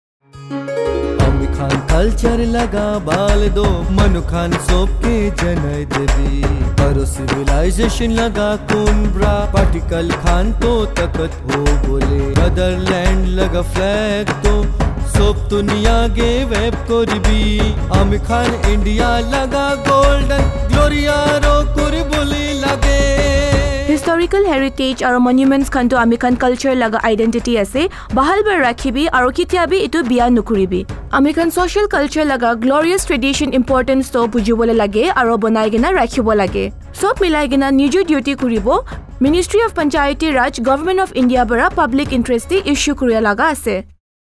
173 Fundamental Duty 6th Fundamental Duty Preserve composite culture Radio Jingle Nagamese